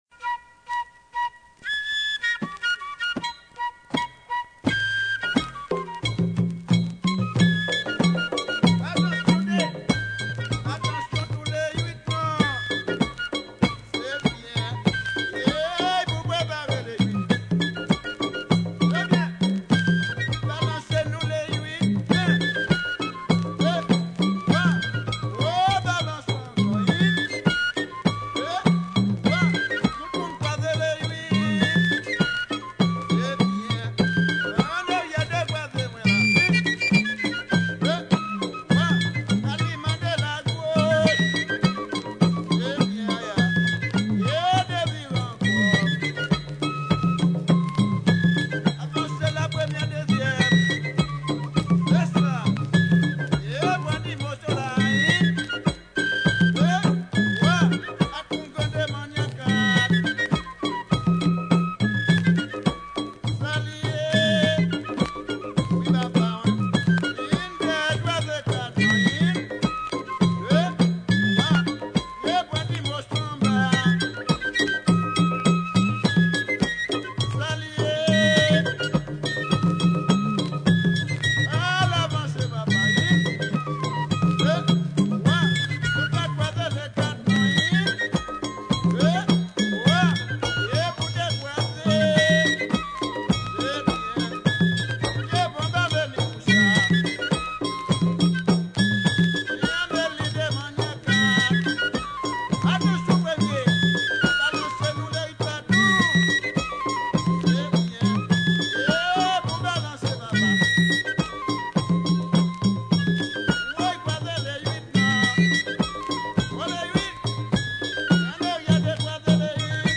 2022 CONTRE DANSE (VIEUX GRIS) FLOKLORE HAITIEN audio closed https